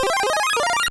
Power Up sound effect from Super Mario Bros. 3